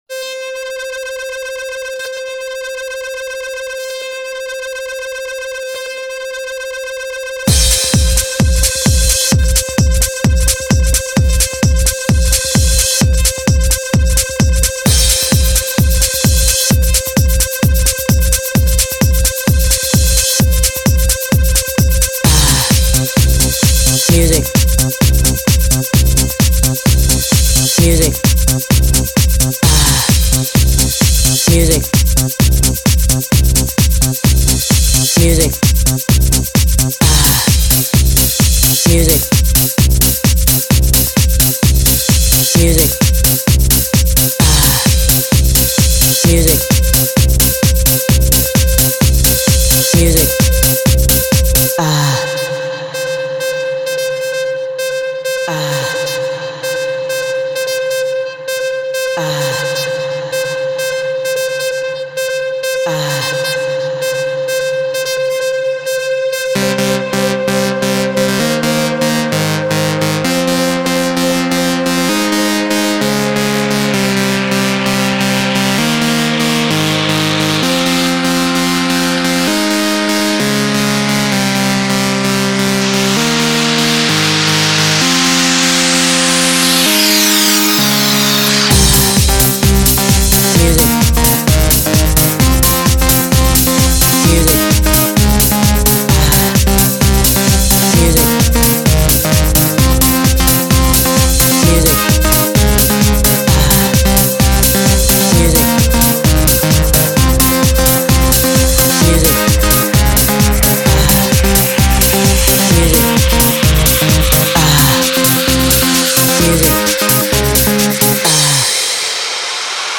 Жанр:Новогодний/Позитивный/Club/Dance
Таги: DJ